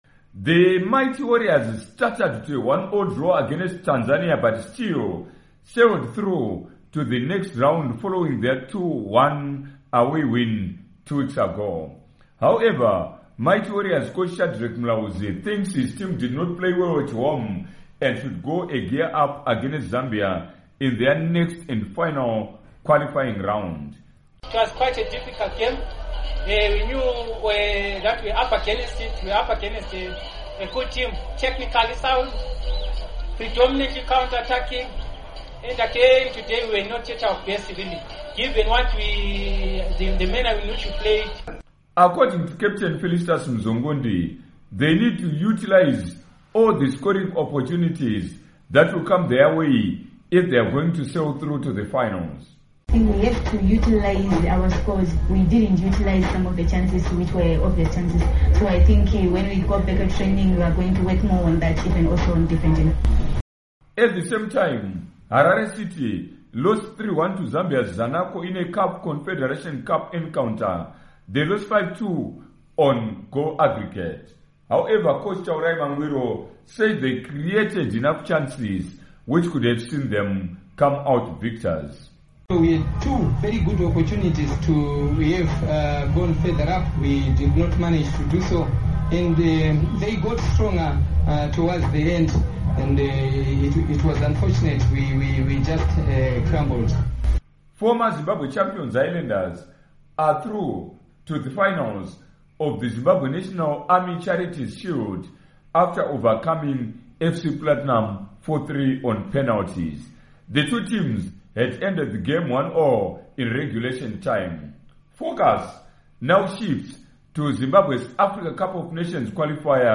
Report on Weekend Games